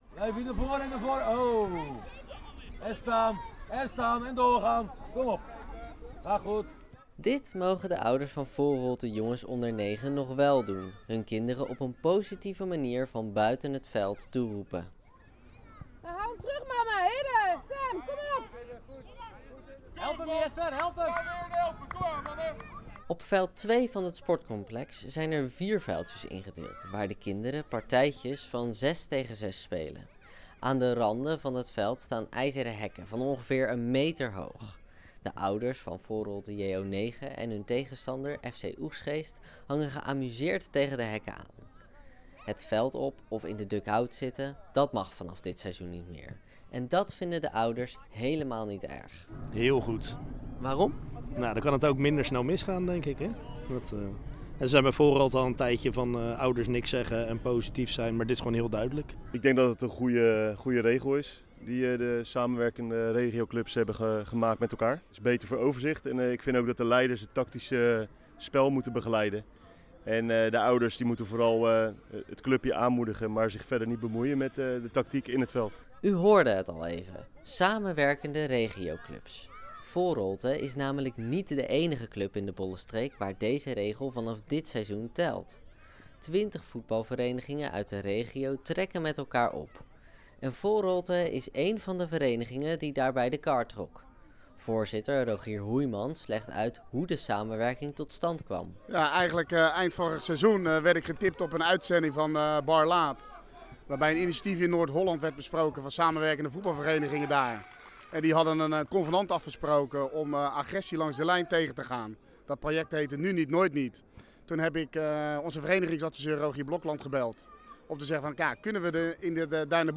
Op zaterdagochtend zijn de ouders op het sportcomplex van voetbalvereniging Foreholte niet helemaal stil.
maakte een reportage bij Foreholte: